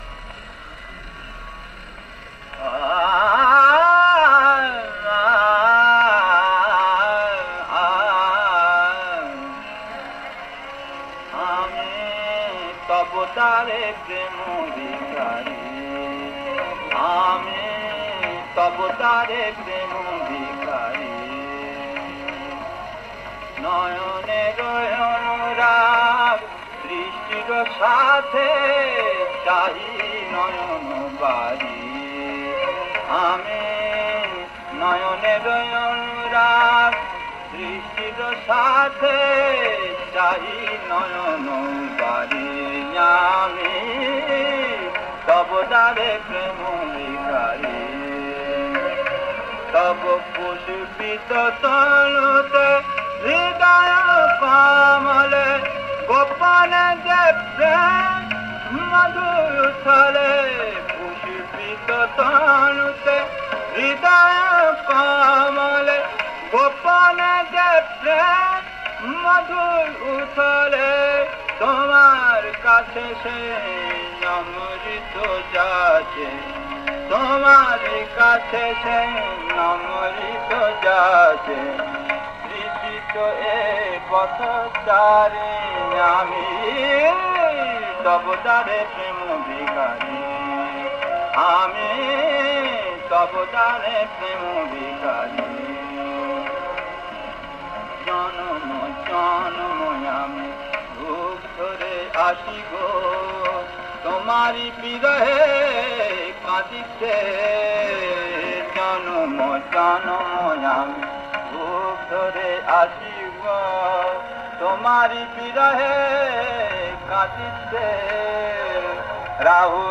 • তাল: ত্রিতাল
• গ্রহস্বর: গা